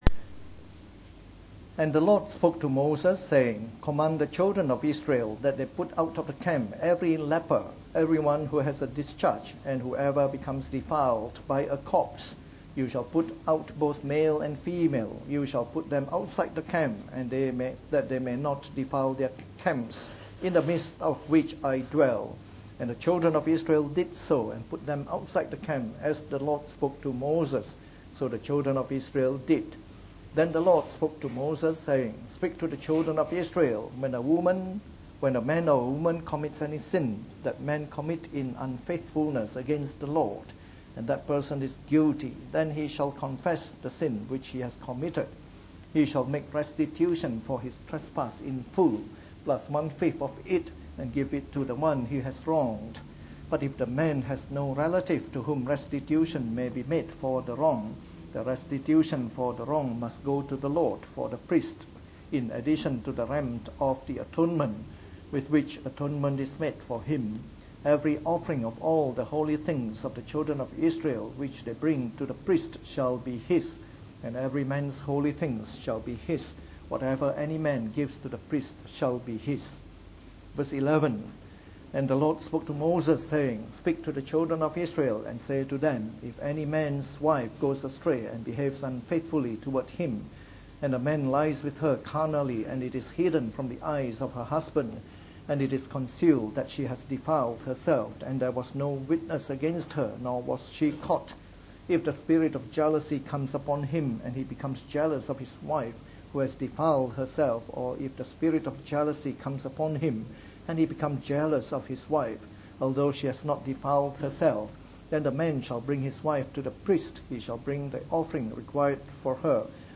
From our new series on the “Book of Numbers” delivered in the Morning Service.